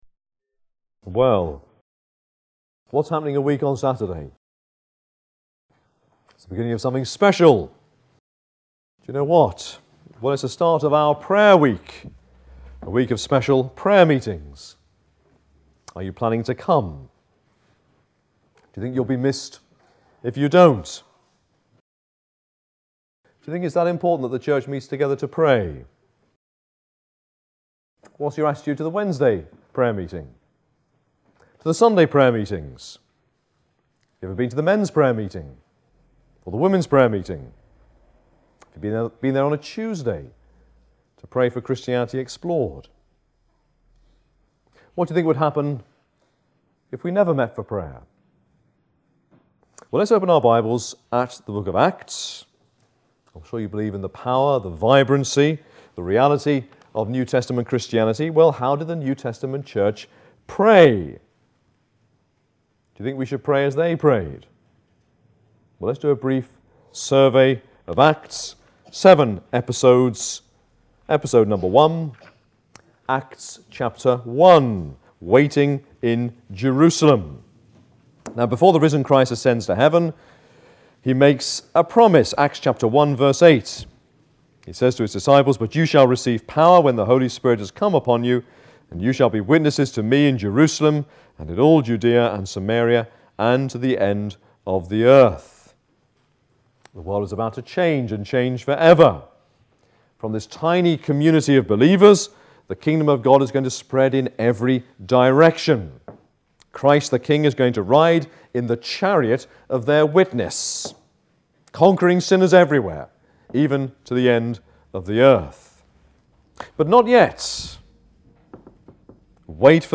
APC - Sermons